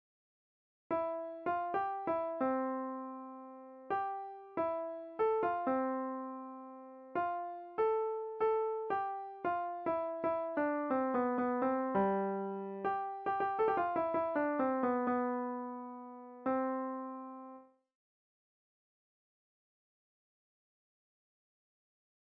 Quintuplets and septuplets. Gradually get faster and faster.